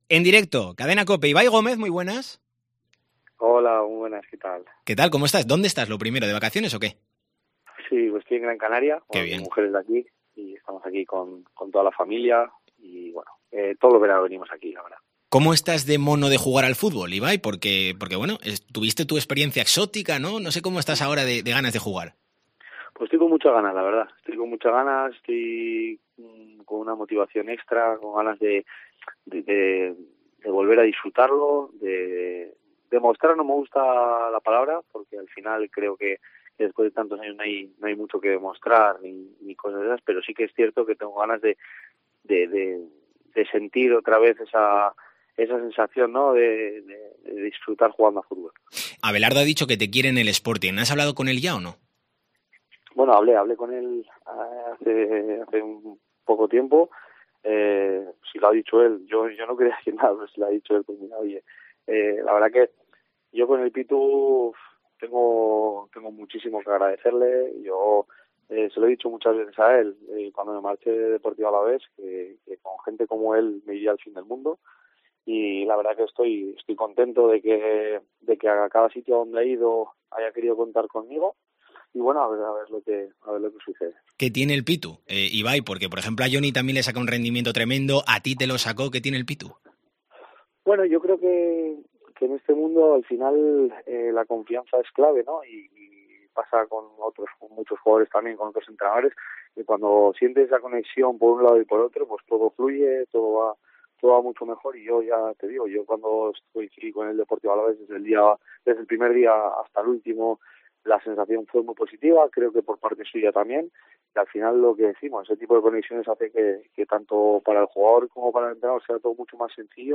ENTREVISTA DCA